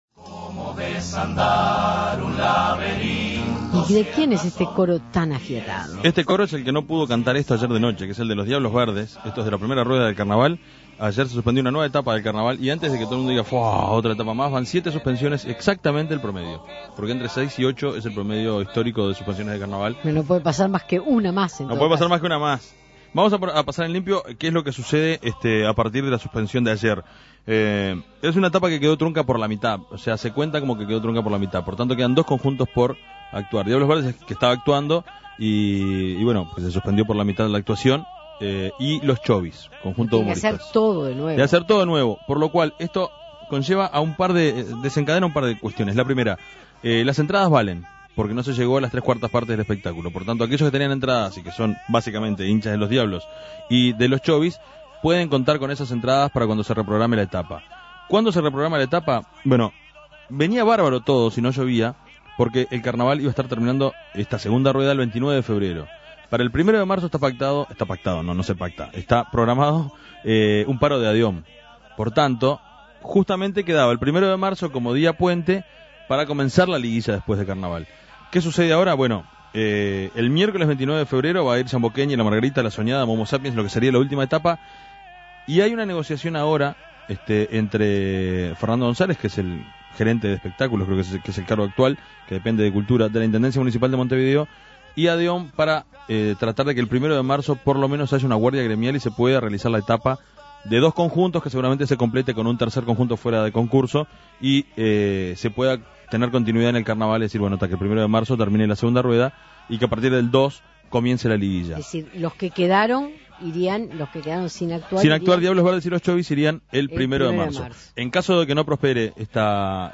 Carnaval 2012 Entrevistas